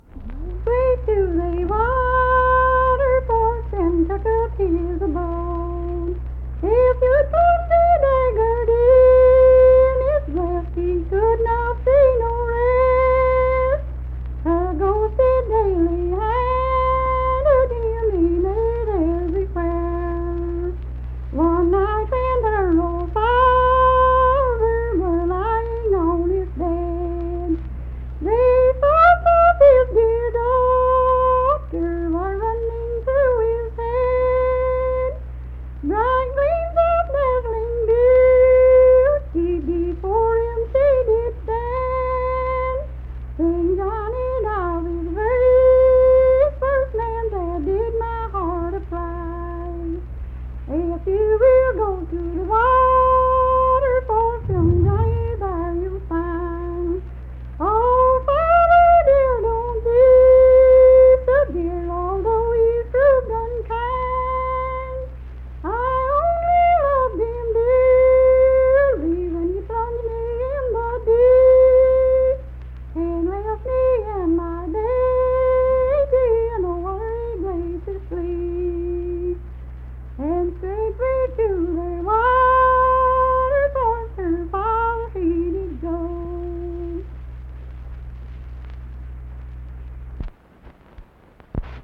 Unaccompanied vocal music
Voice (sung)